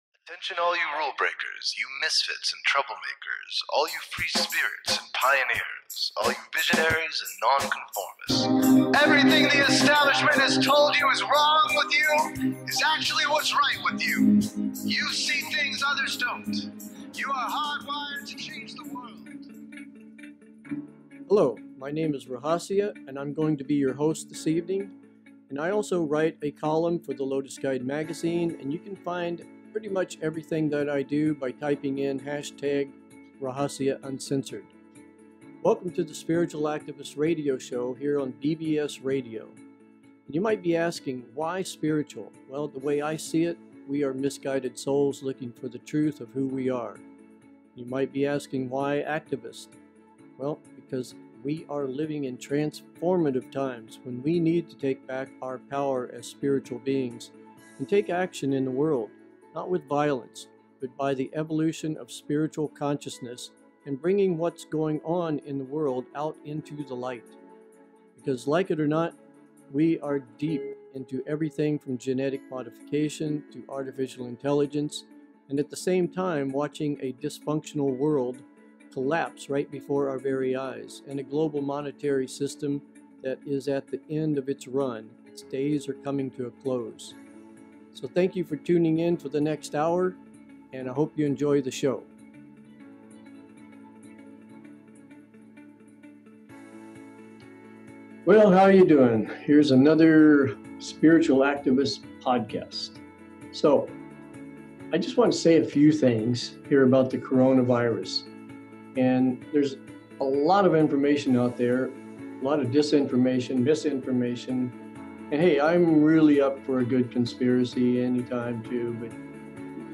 Talk Show Episode, Audio Podcast, Spiritual Activist and Coronavirus-The Tip of the Iceberg-Spiritual Activist Podcast on , show guests , about coronavirus,The Tip of the Iceberg,Spiritual Activist Podcast, categorized as Earth & Space,History,Medicine,News,Politics & Government,Science,Society and Culture,Technology,Theory & Conspiracy